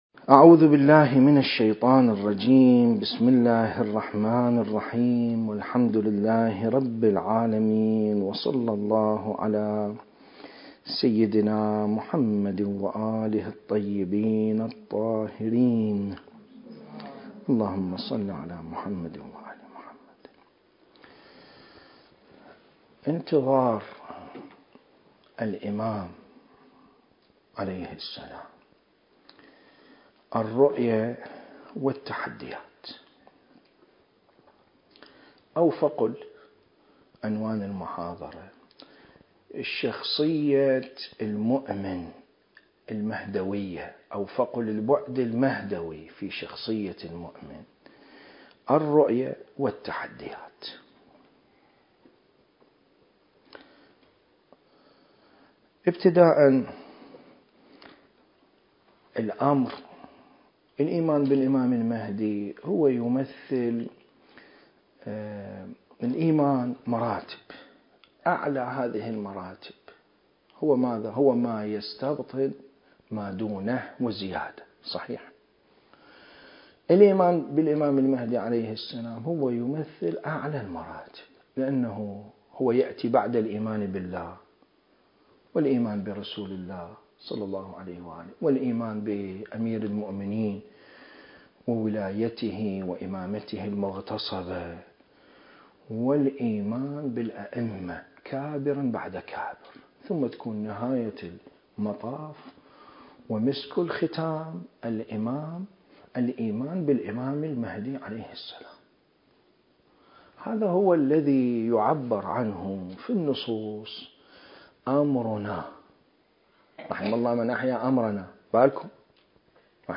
المكان: مكتبة الإمام الخوئي (قدس سره) العامة التاريخ: 2023